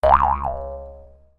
trampoline-cartoon-03.ogg